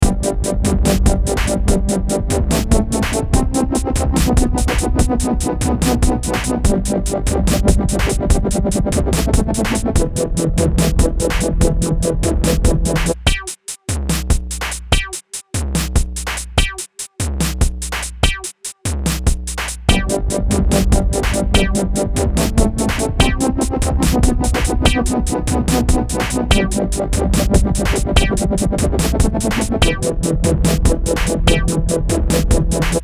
Dubstep?
Instrumental